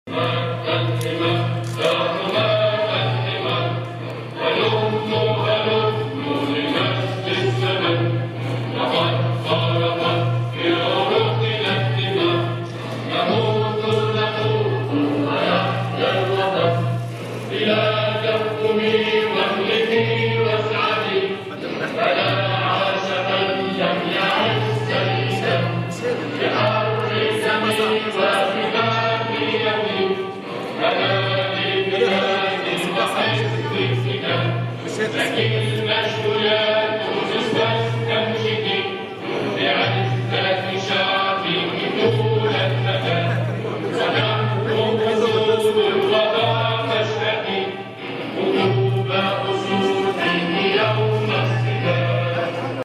التسجيل الذي عُرض خلال افتتاح معرض الكتاب